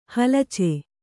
♪ halace